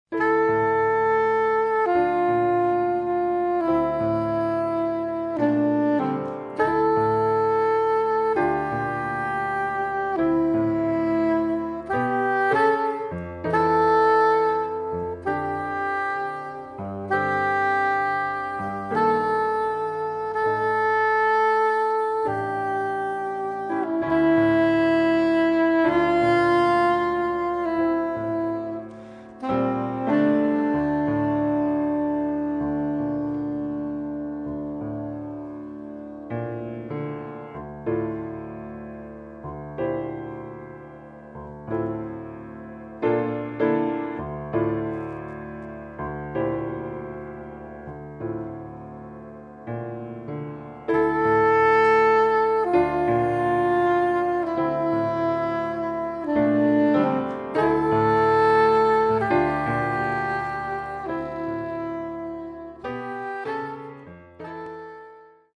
sax soprano et alto
guitare, trompette
contrebasse
batterie